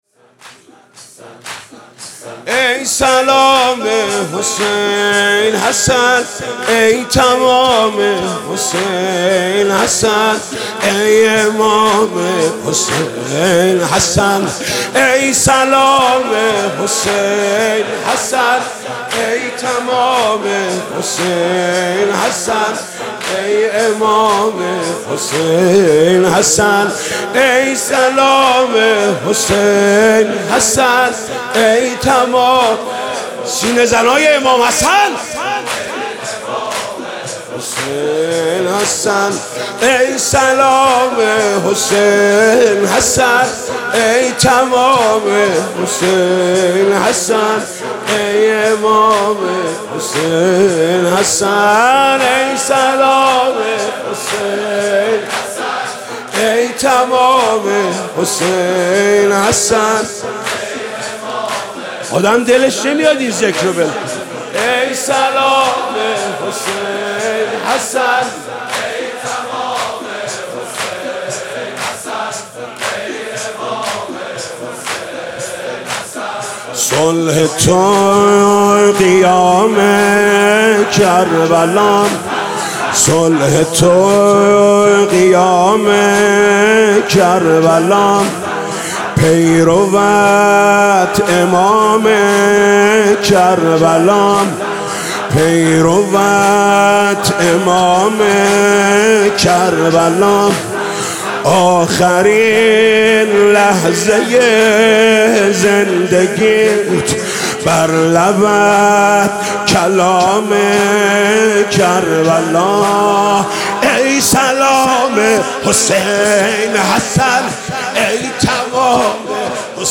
ای سلام حسین حسن ای تمام حسین حسن ای امام حسین حسن محمود کریمی مداحی شب شهادت امام حسن مجتبی (ع) 15 آبان 95